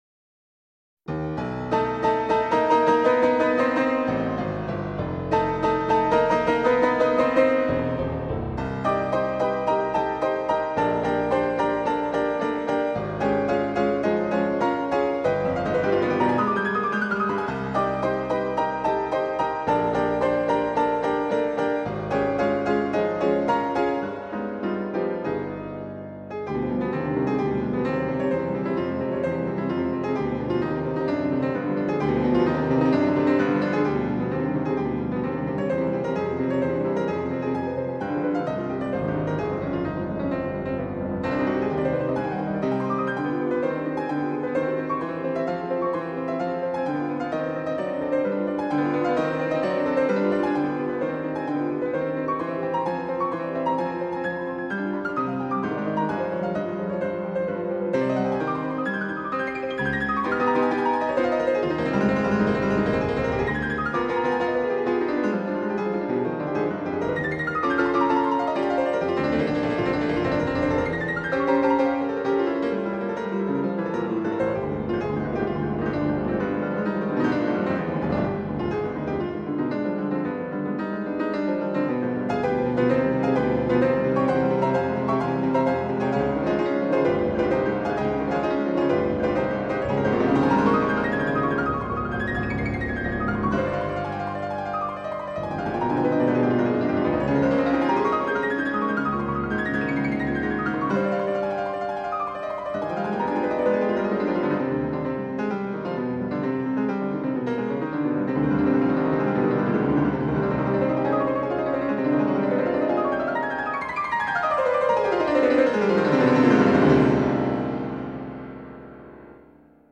Envolée lyrique puissante au piano